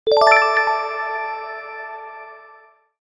Звуки банковской карты
Звук оплаты картой для видеомонтажа